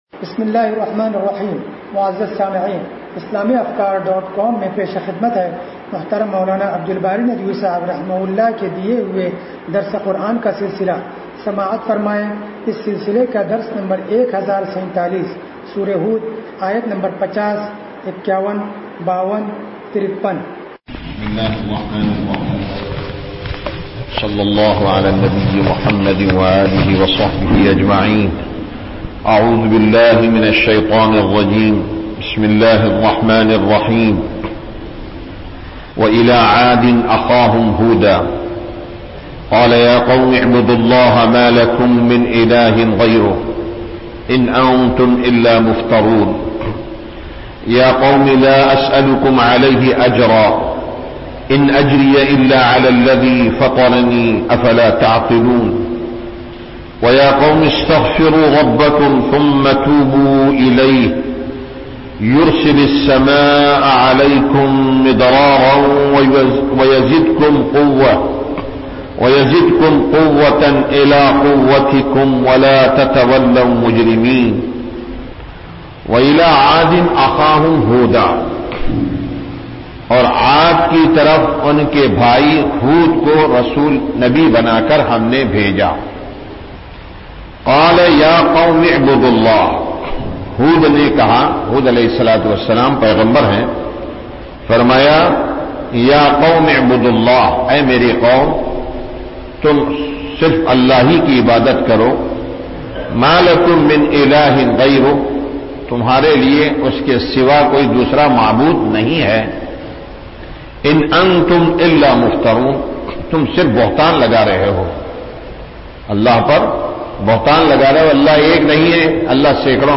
درس قرآن نمبر 1047